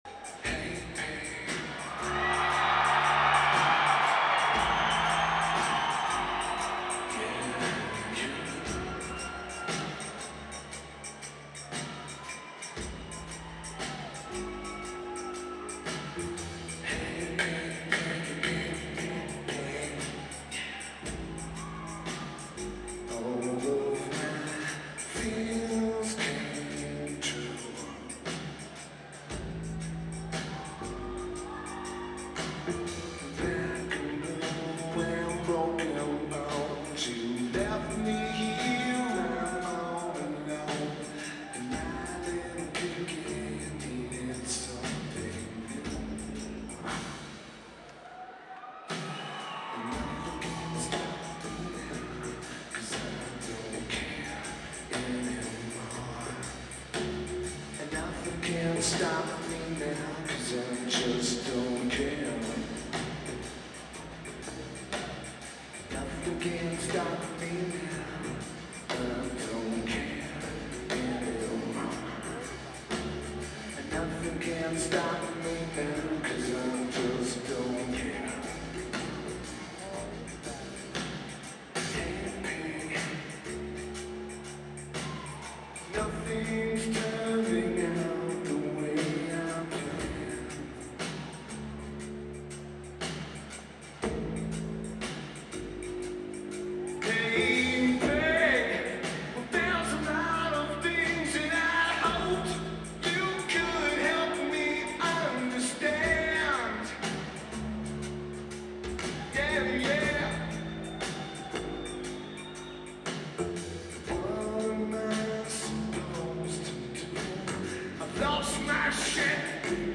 Lineage: Audio from Video - AUD (Canon DM-50 + Canon HV20)